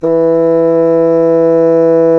Index of /90_sSampleCDs/Roland LCDP04 Orchestral Winds/CMB_Wind Sects 1/CMB_Wind Sect 7
WND BSSN E3.wav